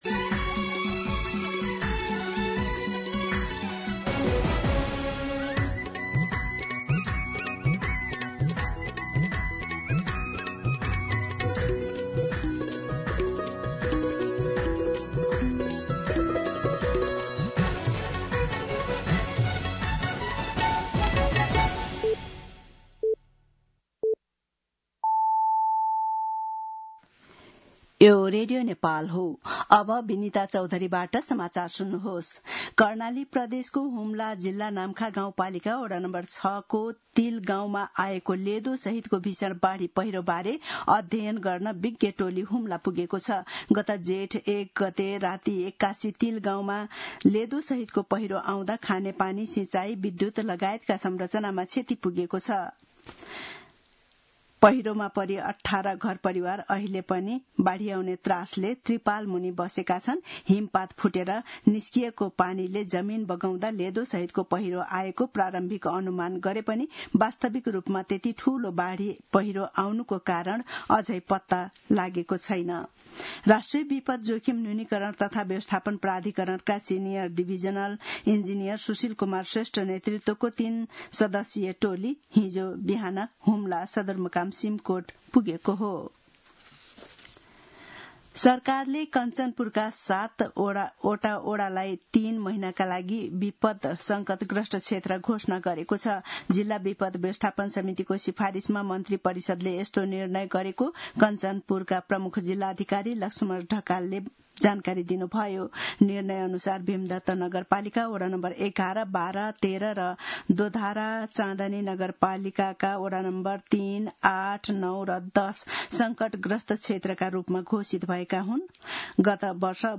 मध्यान्ह १२ बजेको नेपाली समाचार : ८ जेठ , २०८२